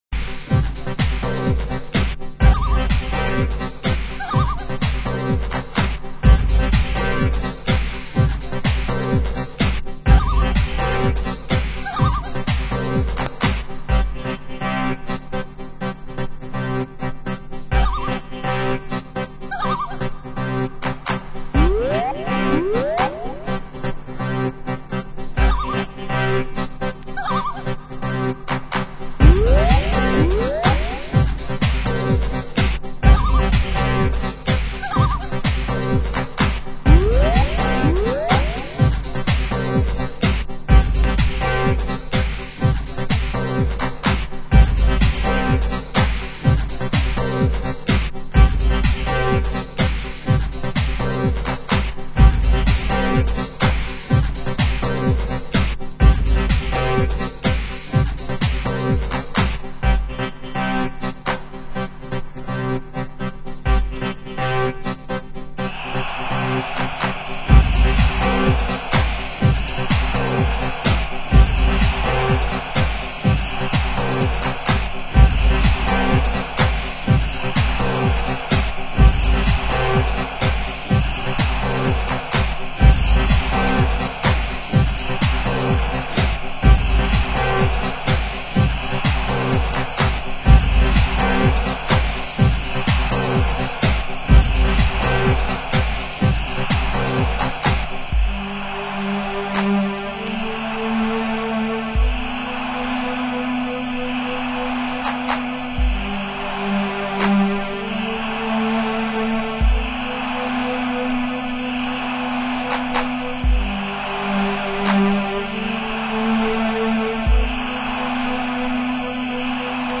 Genre: US Techno